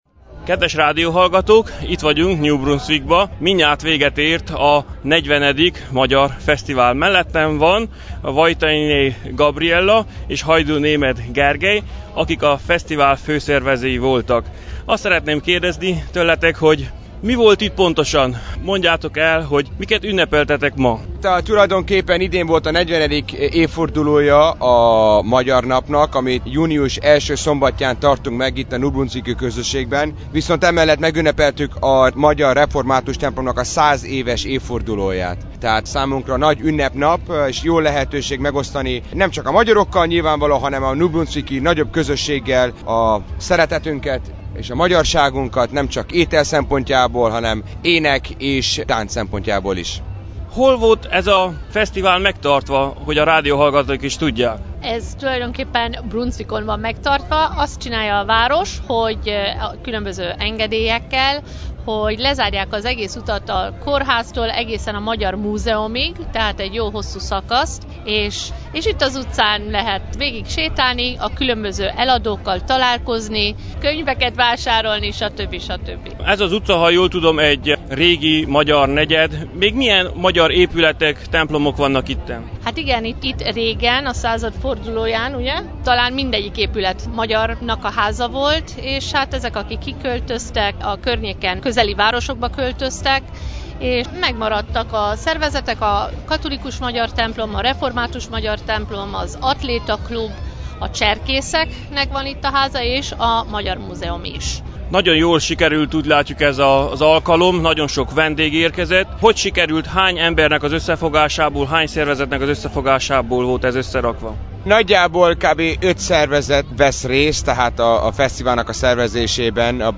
Rendezok.mp3